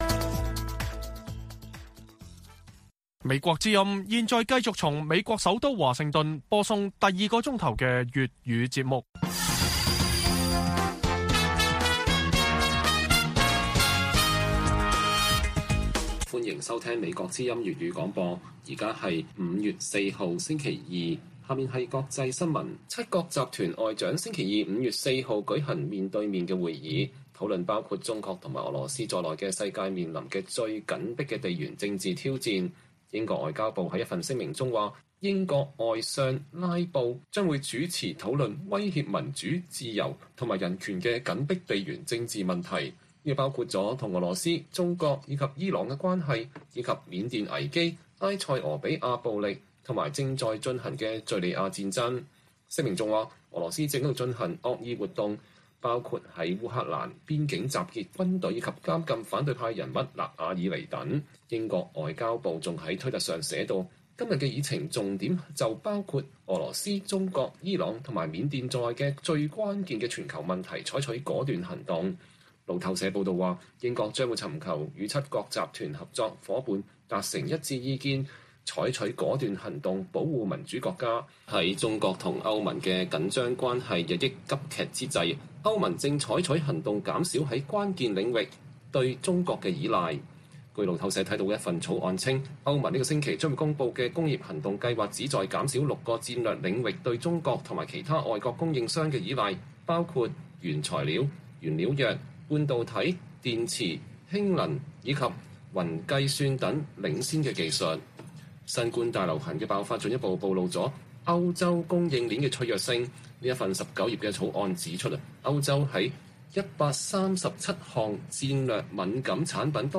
粵語新聞 晚上10-11點